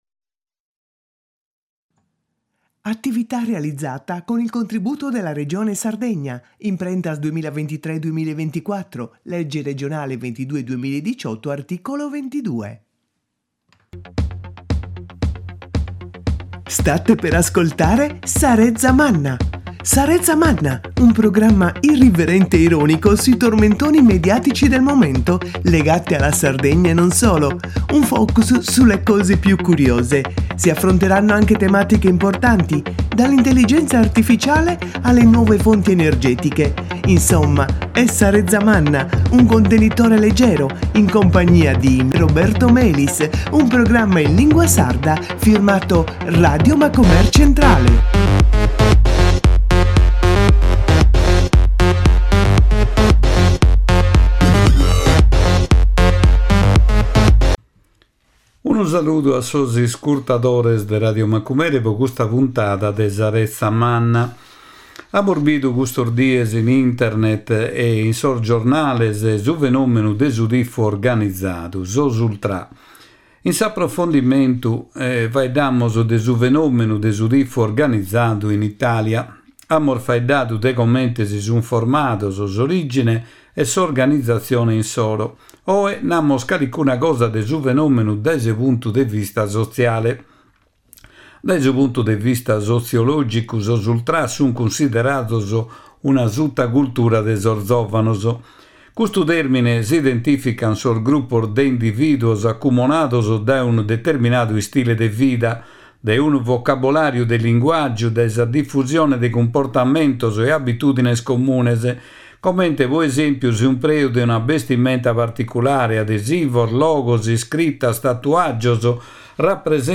Cun nois Mario Roccu, assessore a sa laurantza de su comune de Lei.